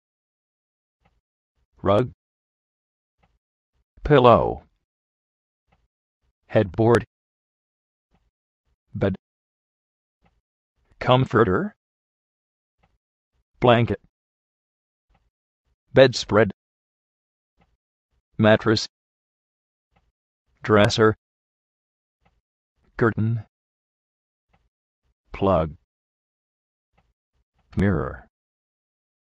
pílou
jédbord
kómforter
mátres
kérten